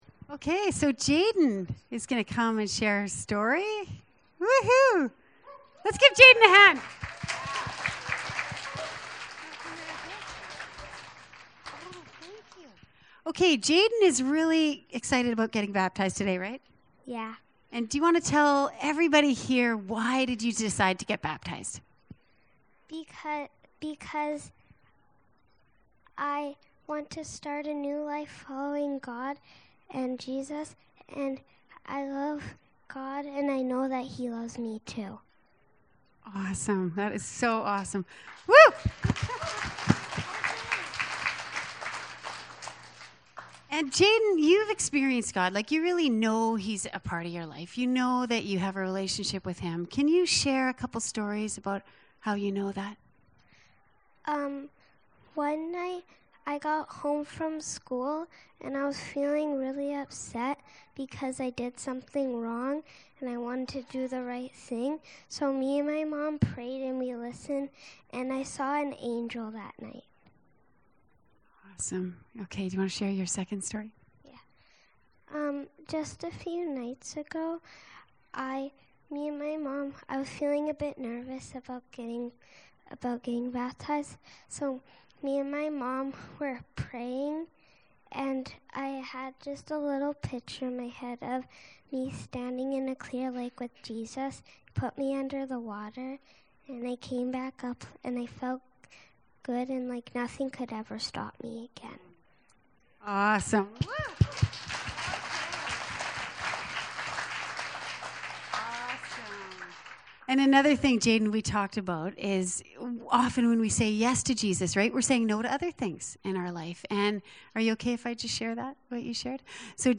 Baptism Service Type: Downstairs Gathering Series